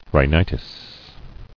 [rhi·ni·tis]